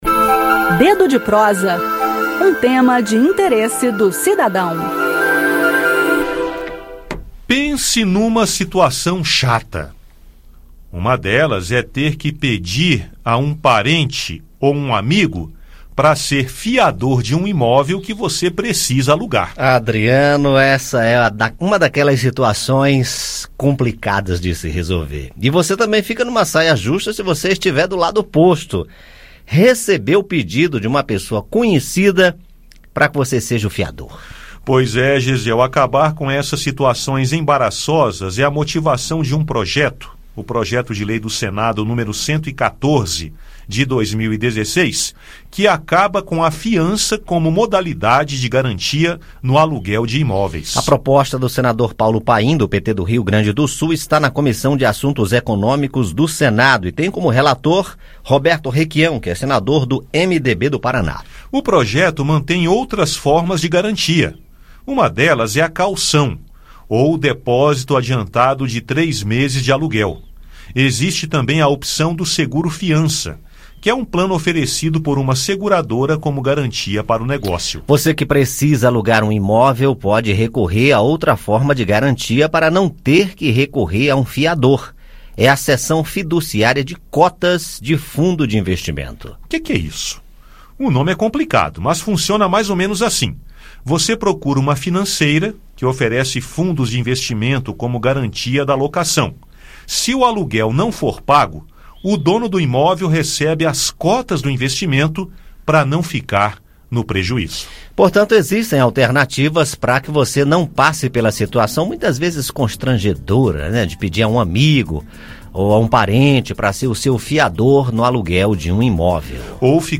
Ouça o áudio com o bate papo sobre o assunto.